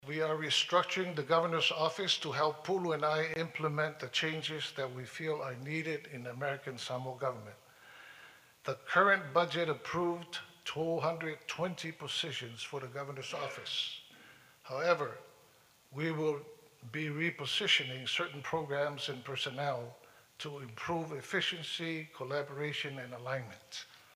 In his State of the Territory address, at the opening of the Fono yesterday, Governor Pulaalii Nikolao Pula made mention of changes in the structure of the Governor’s Office staff.